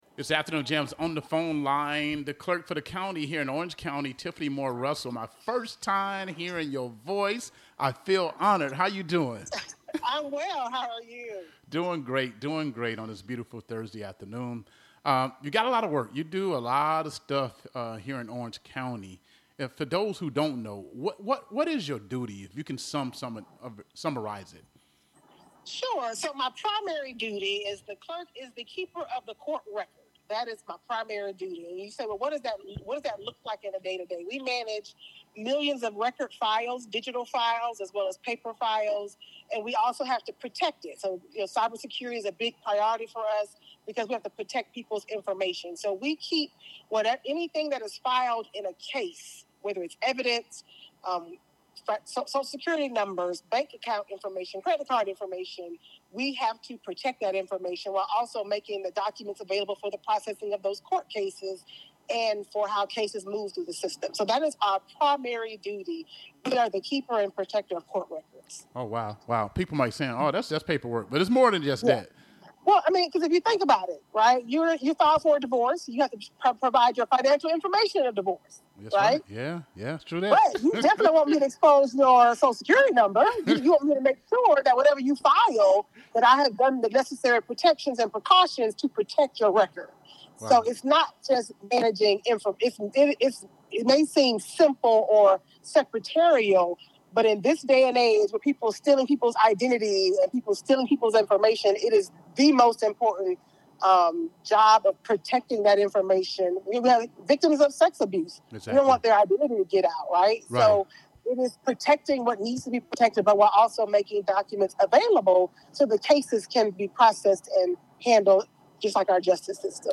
This interview is a must-listen for those looking to understand more about the pivotal changes and support structures being implemented in the heart of Orange County.